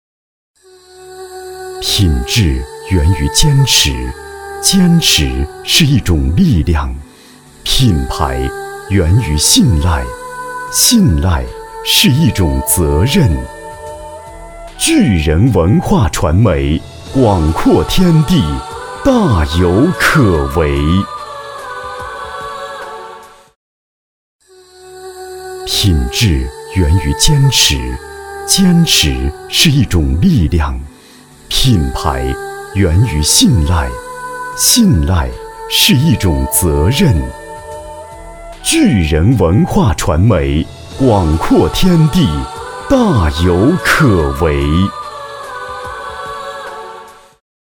国语青年大气浑厚磁性 、积极向上 、男专题片 、宣传片 、50元/分钟男S39 国语 男声 宣传片-招商银行-银行宣传片-沉稳大气 大气浑厚磁性|积极向上 - 样音试听_配音价格_找配音 - voice666配音网
国语青年大气浑厚磁性 、积极向上 、男专题片 、宣传片 、50元/分钟男S39 国语 男声 宣传片-招商银行-银行宣传片-沉稳大气 大气浑厚磁性|积极向上